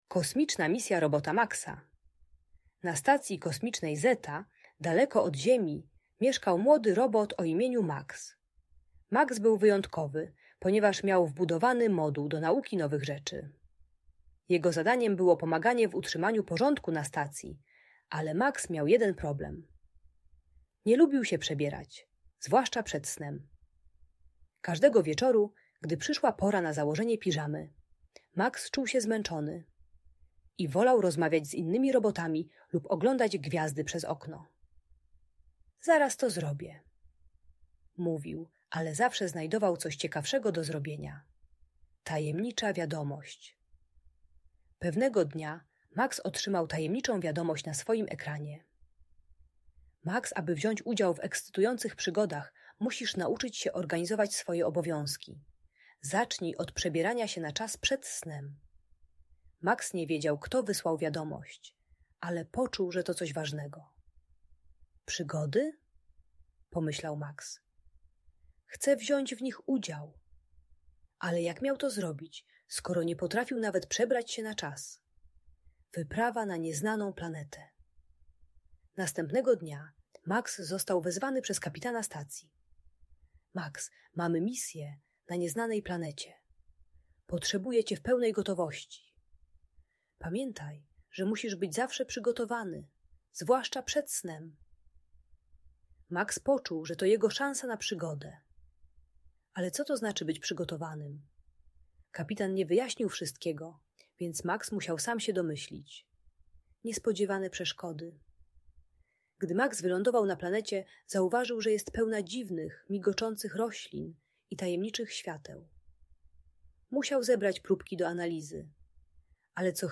Kosmiczna Misja Robota Maxa - historiao przygodach w kosmosie - Audiobajka